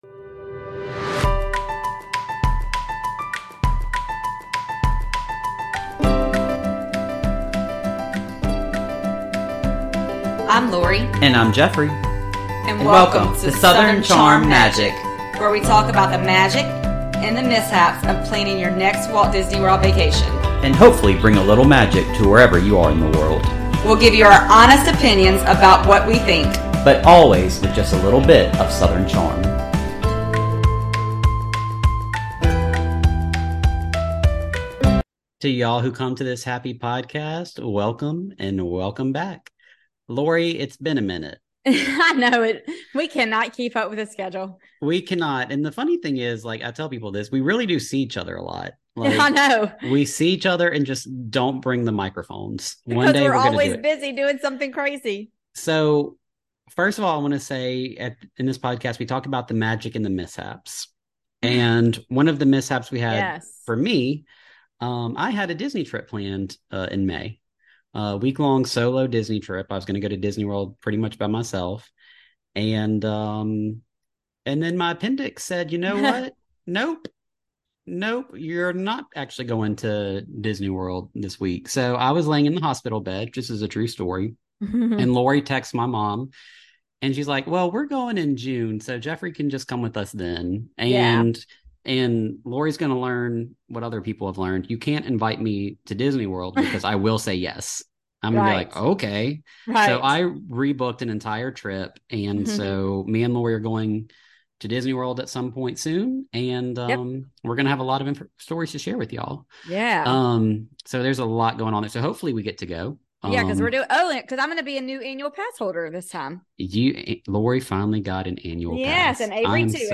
In Southern Charmed Magic, two ultimate Disney fans from South Louisiana talk about the magic and mishaps of planning a Walt Disney World vacation, with practical tips to help you plan your best Disney trip.